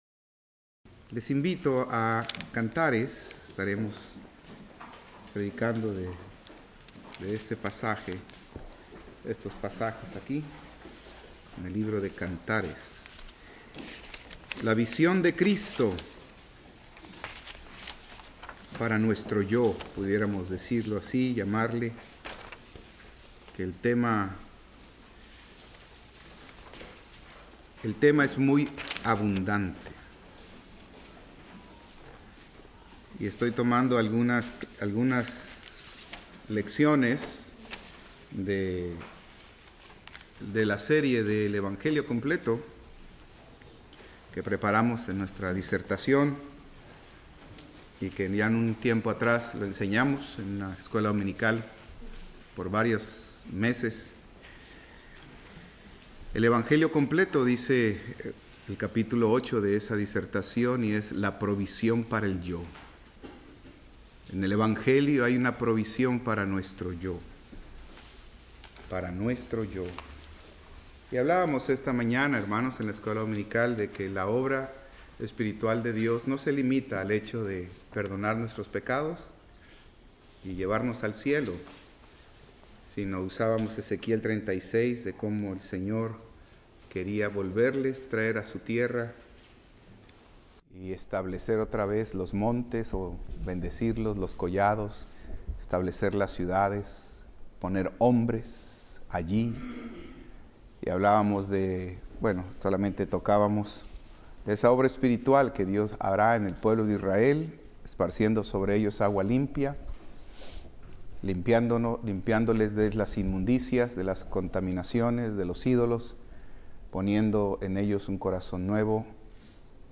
Servicio Matutino